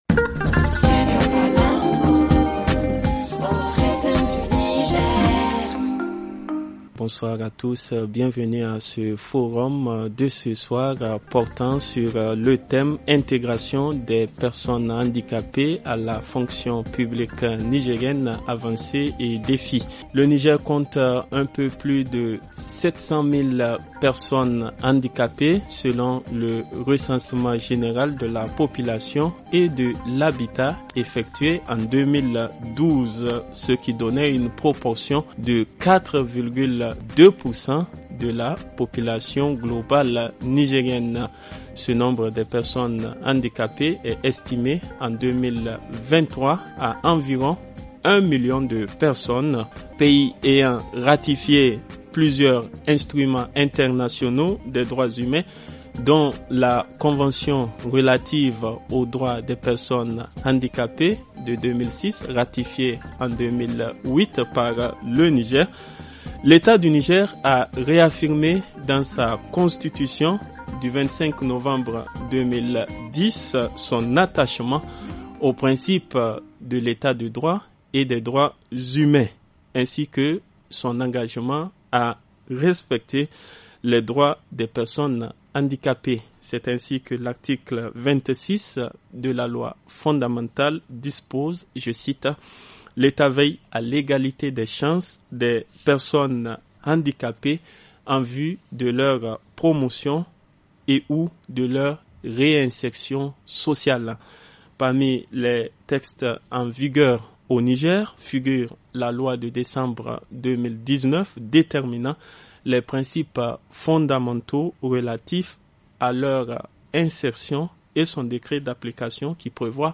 [Rediffusion] Intégration des personnes handicapées à la fonction publique au Niger : avancées et défis - Studio Kalangou - Au rythme du Niger
FR Le forum en français Télécharger le forum ici.